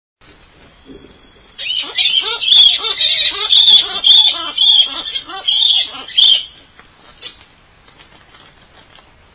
不穏な笑い声　天敵現る
たんかん畑　シークワーサー周辺